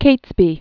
(kātsbē), Robert 1573-1605.